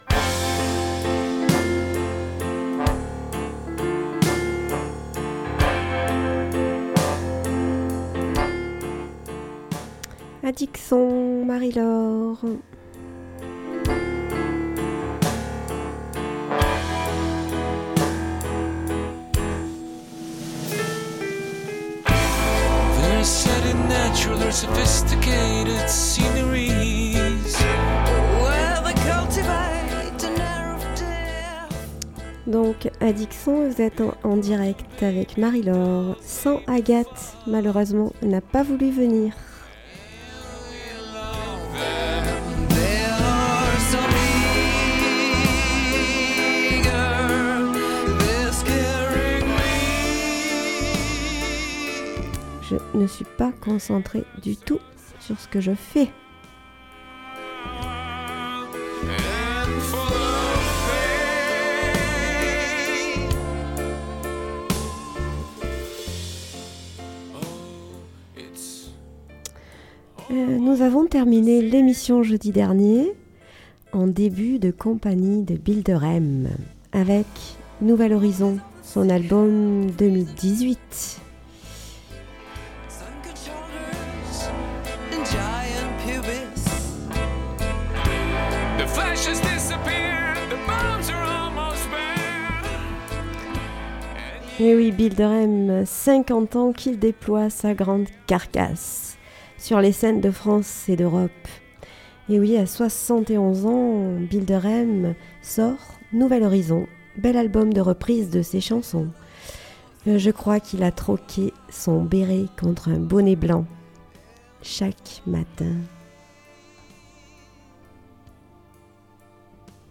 Addic Son - Emission musicale du 03 janvier 2019